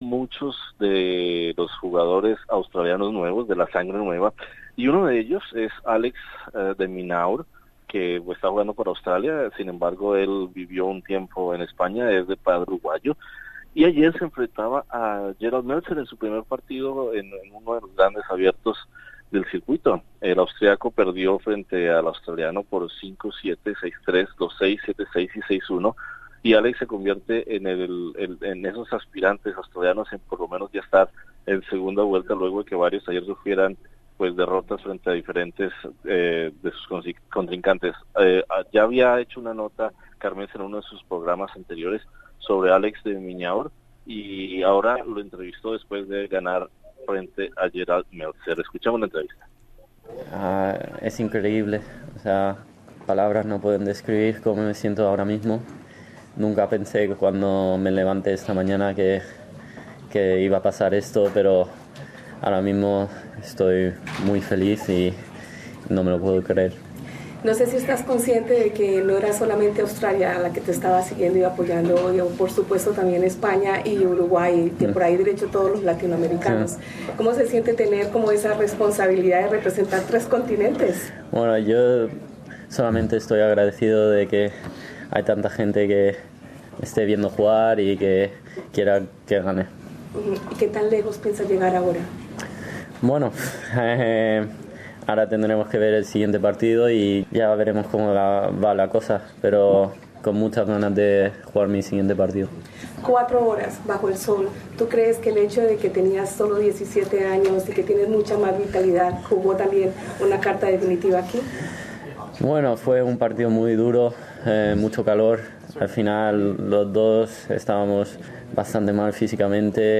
Conversamos con el joven tenista de 17 años, hijo de madre española y padre uruguayo, quien se encuentra en la posición 386 del ranking mundial, y que acaba de pasar a la segunda ronda del abierto.